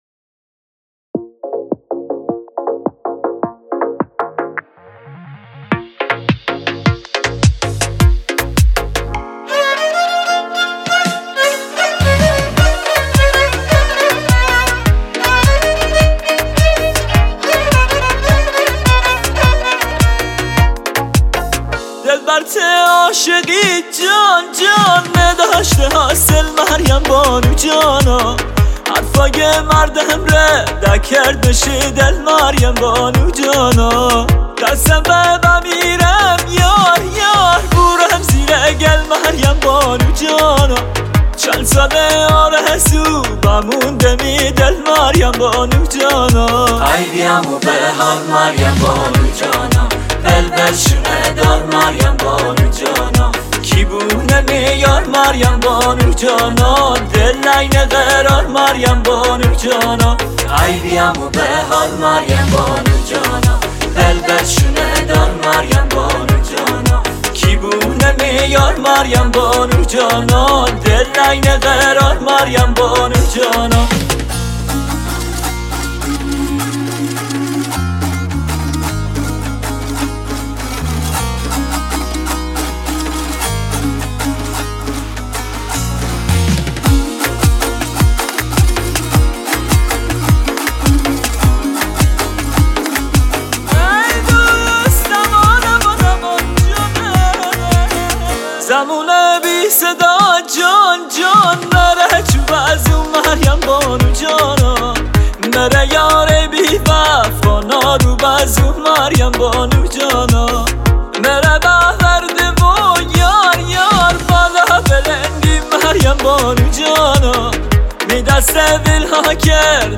برای شروع خوبه اما ساز کم داشت.